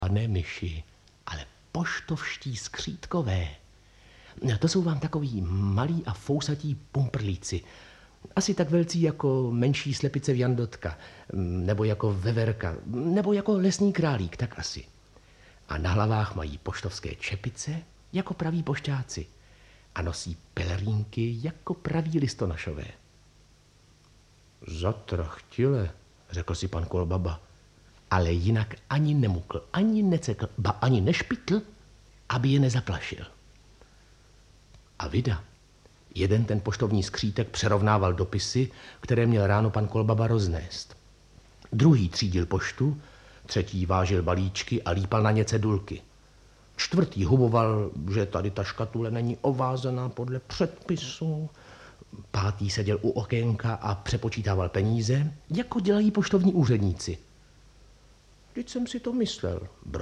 Audiobook
Read: Václav Voska